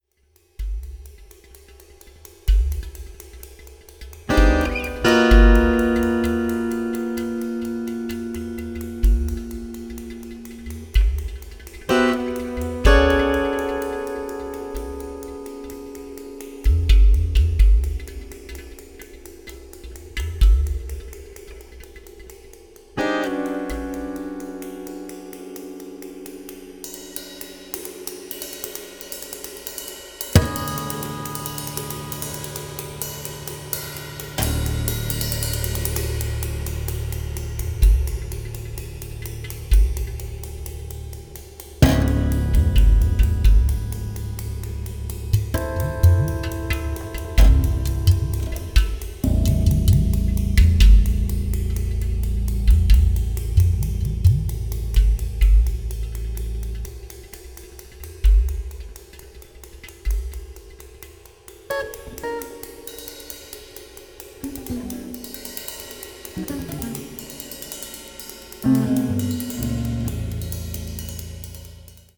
30-string Contra-Alto guitar
Percussion